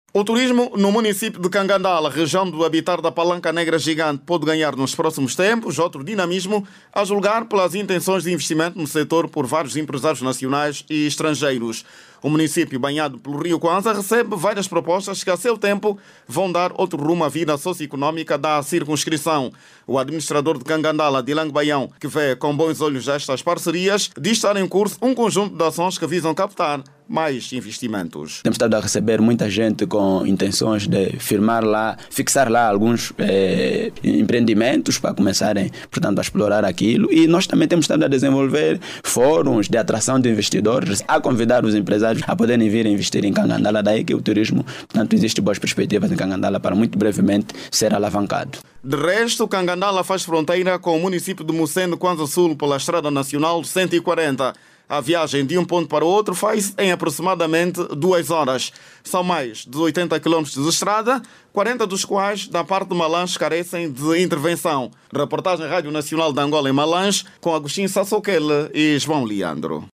O turismo no município de Cangandala, Província de Malanje pode ganhar nos próximos tempos outro dinamismo a julgar pelas intenções de investimento no sector por vários empresários nacionais e estrangeiros. O administrador de Cangandala, região do habitat da Palanca Negra Gigante, fala em trabalho aturado com vista a atracção de mais investidores para o município.